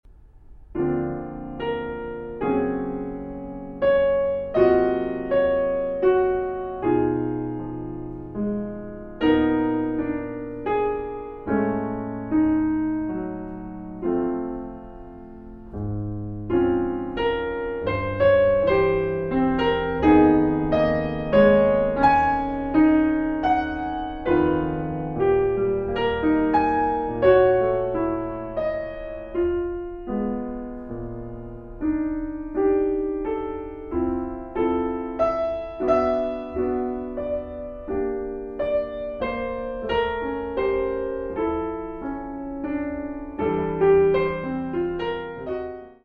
Calm (3:06)